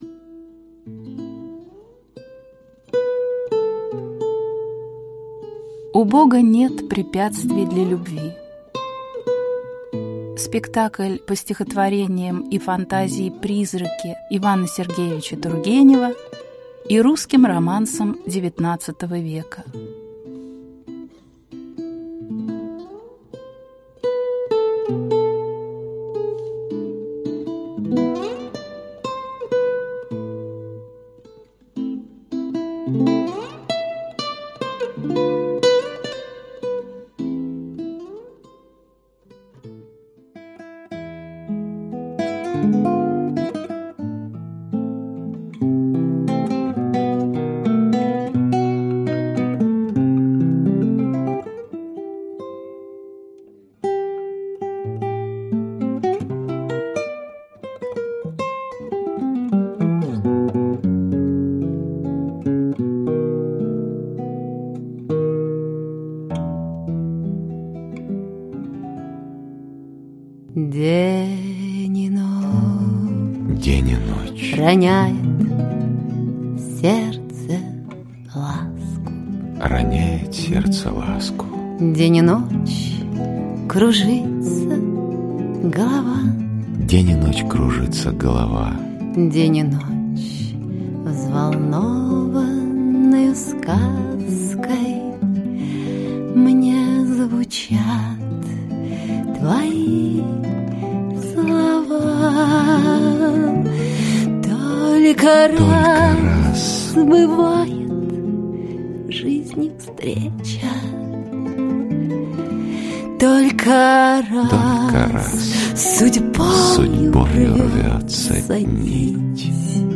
Аудиокнига У Бога нет препятствий для любви. Аудиоспектакль | Библиотека аудиокниг
Аудиоспектакль Автор Иван Тургенев Читает аудиокнигу Сергей Чонишвили.